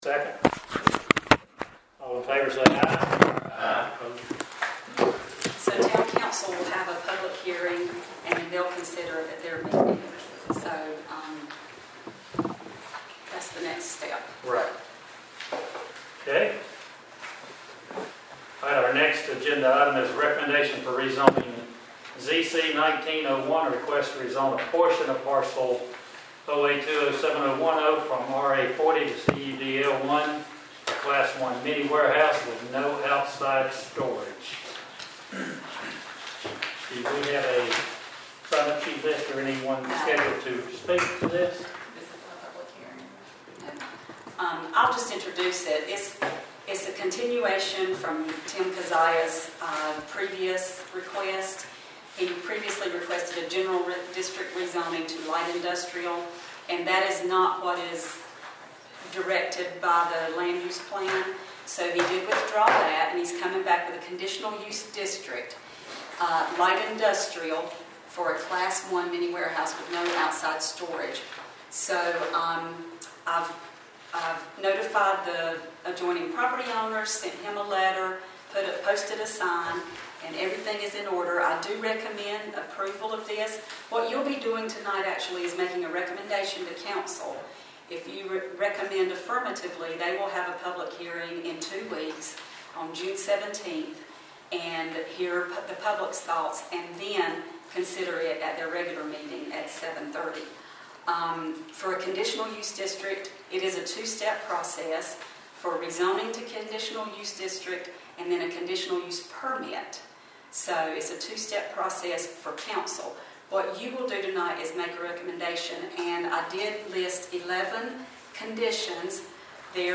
Audio of Town Council Meetings
Unionville Town Hall Hearing 1